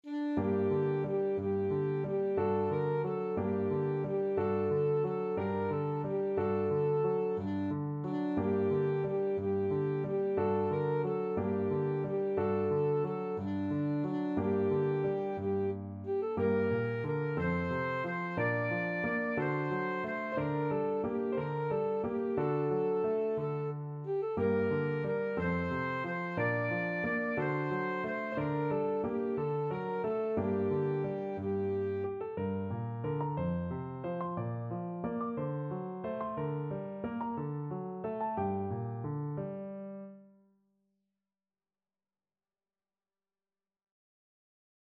Alto Saxophone
~ = 90 Munter
6/8 (View more 6/8 Music)
D5-D6
Classical (View more Classical Saxophone Music)